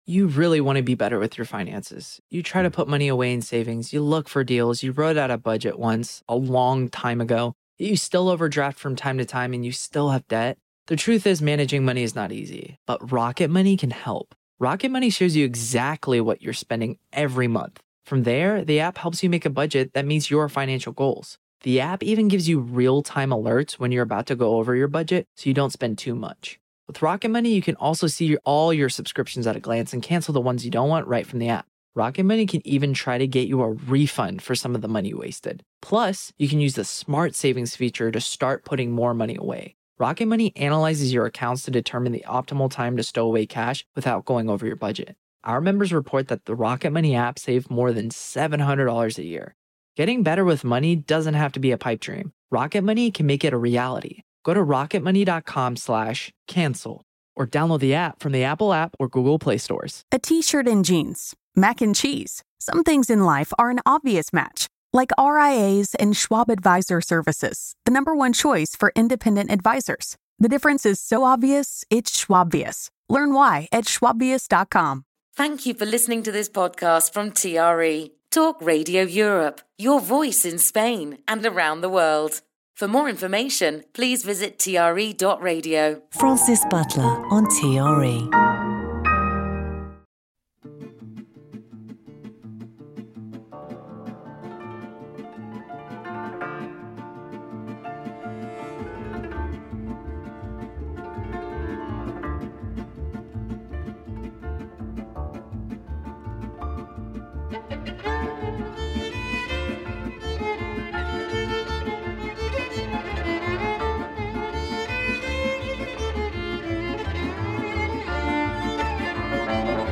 Classical Music Show